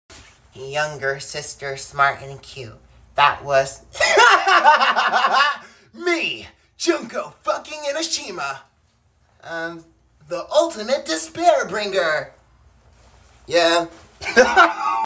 That Junko audio that’s going around but it’s a guy and he forgets the words.